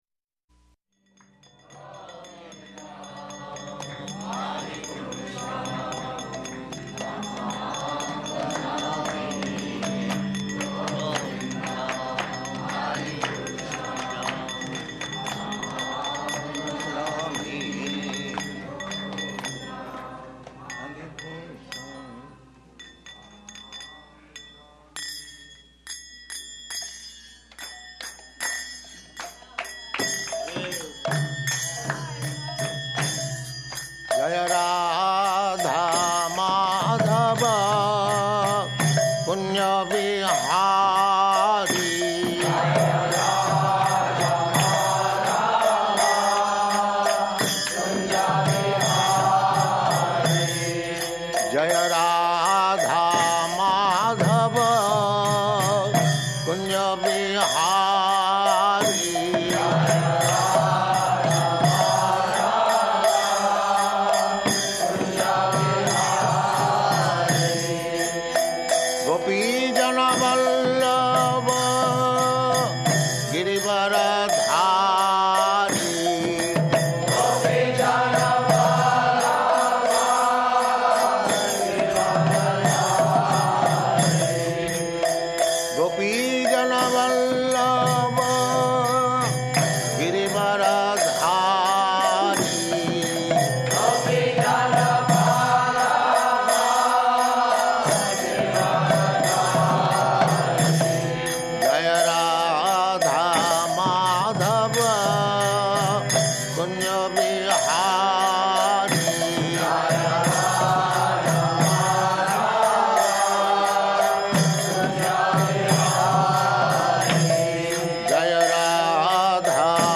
Type: Srimad-Bhagavatam
Location: Los Angeles
[ Govindam prayers playing]